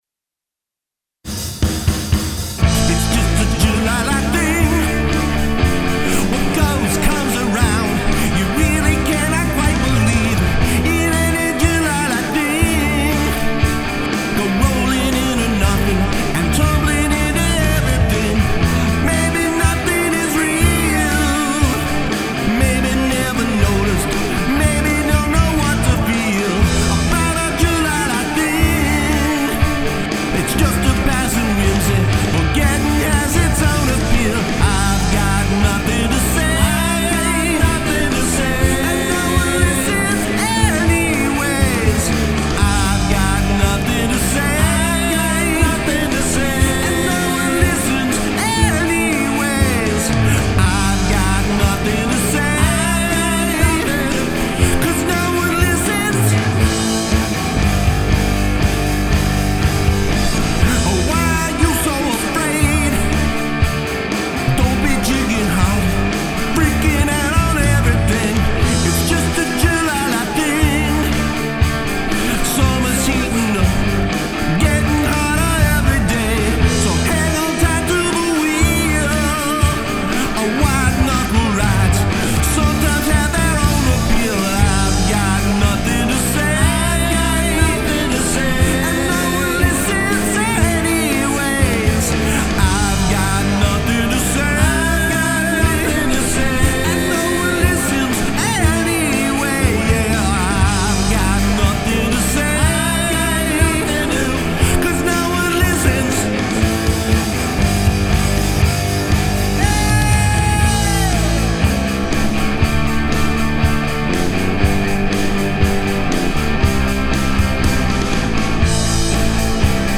As others have said....very Stones. :cool: The overall sound has a bit of hollowness to it....like it was recorded in an empty room sort of. Not bad, but just has somewhat of a "distant" vibe to it...and you could punch up the overall level a bit.